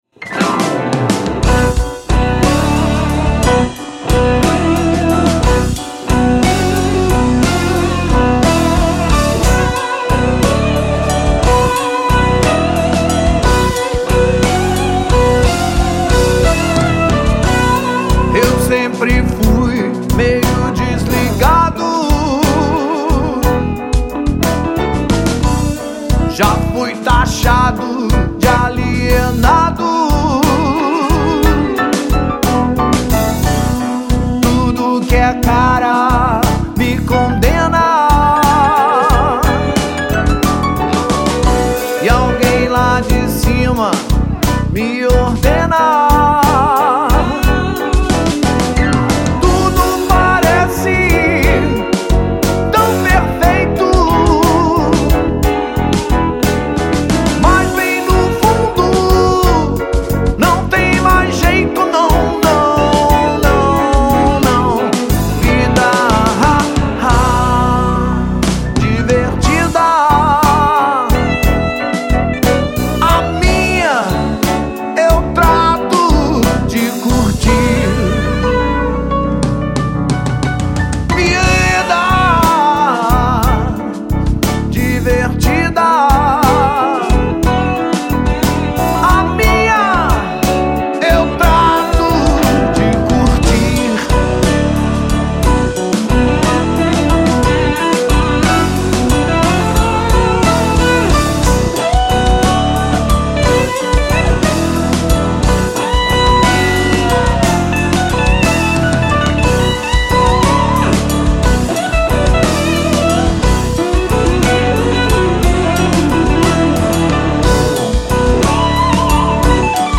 2322   04:14:00   Faixa:     Rock Nacional